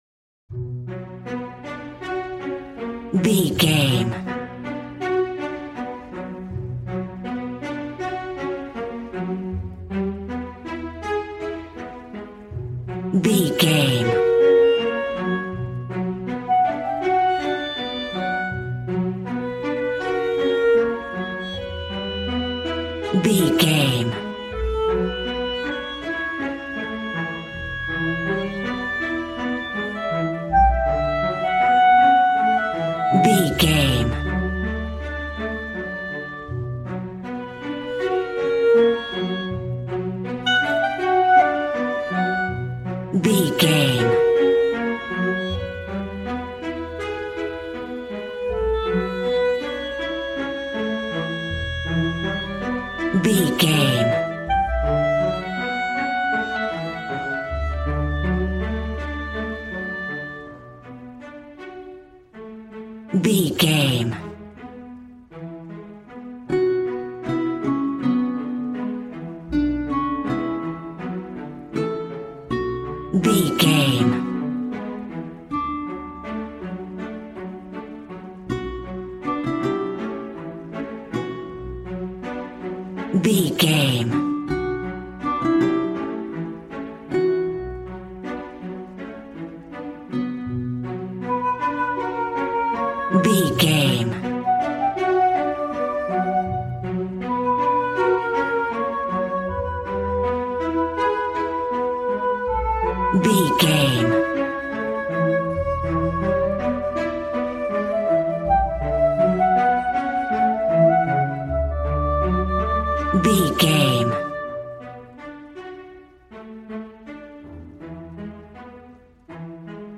A warm and stunning piece of playful classical music.
Regal and romantic, a classy piece of classical music.
Aeolian/Minor
regal
piano
violin
strings